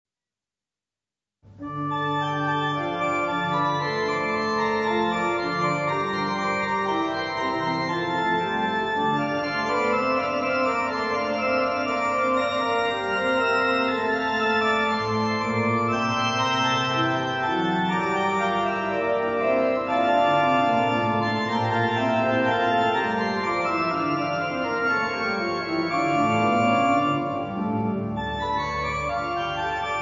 organo